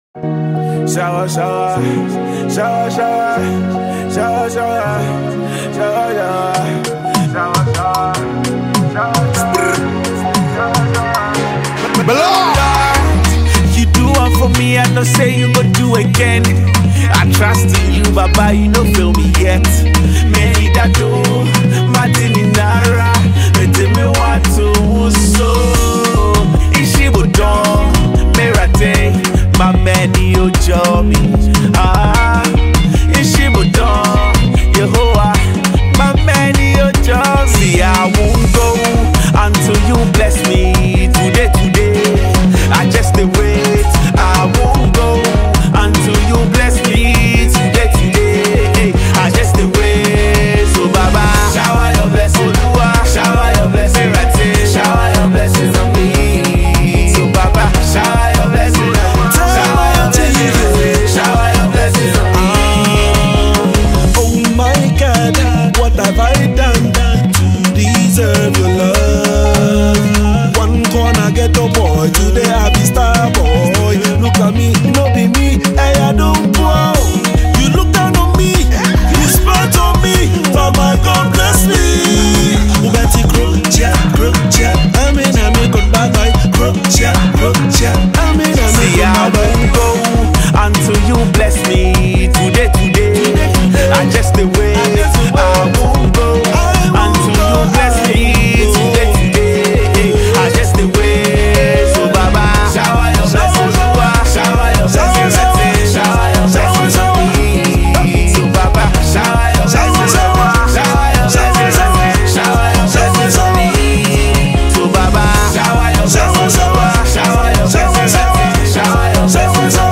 urban vs contemporary gospel music
upbeat, high-energy gospel vibe
forceful vocal delivery